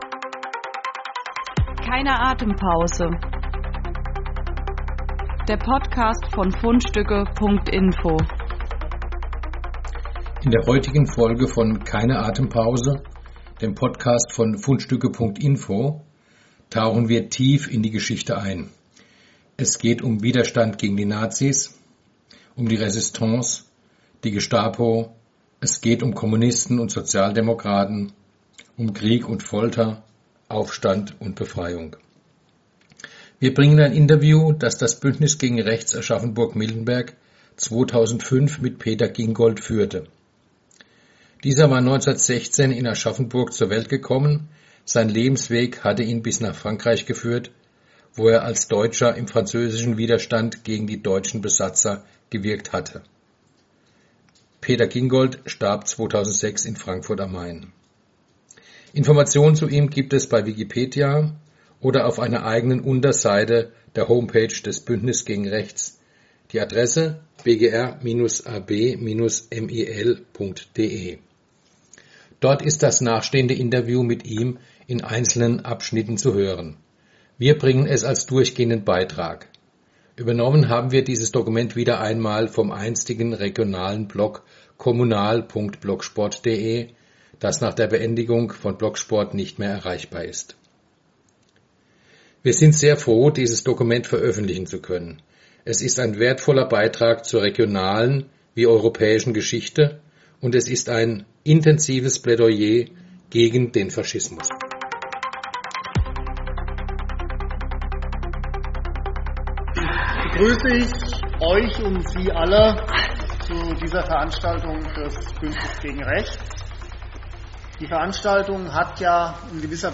Als einer von viel zu wenigen Deutschen kämpfte er in der französischen Résistance aktiv gegen die Nazi-Besatzung. In einem längeren Interview berichtete er 2005 über sein Leben.